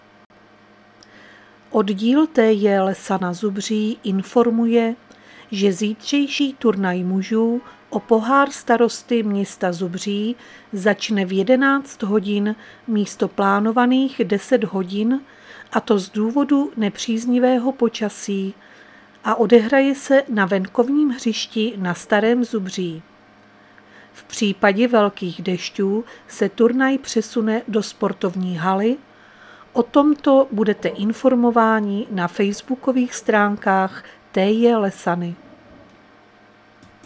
Záznam hlášení místního rozhlasu 2.8.2024